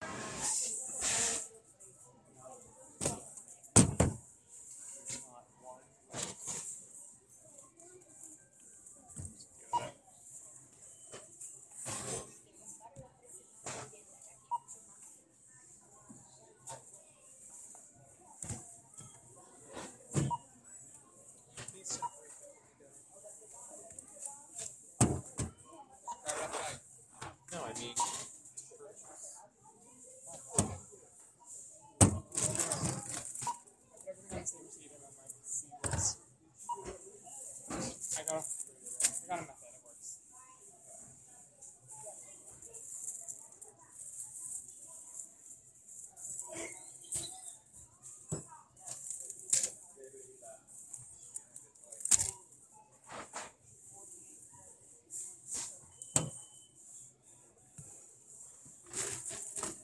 Field Recording 6
10 Mar 2016, 7:55 pm, Westbury Walmart
sounds: placing items on belt, register beeping, talking